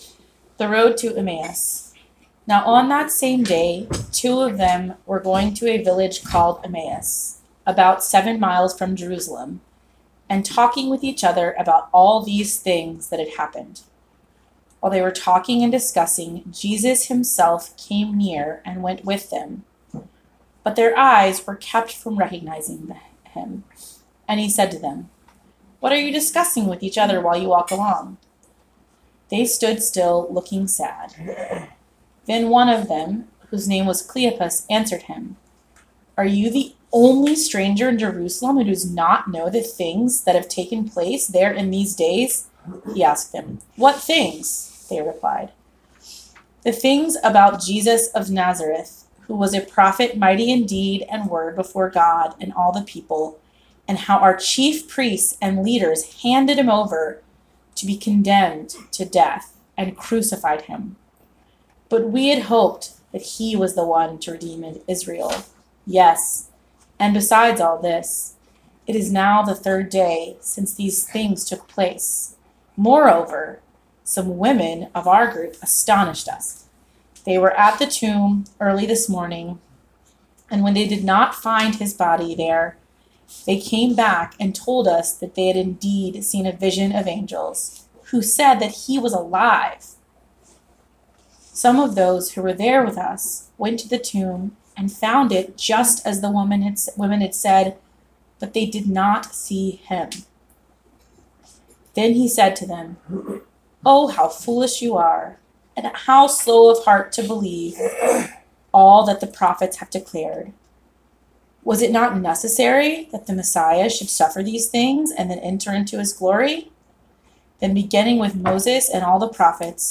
Jan 27, 2019 Sermon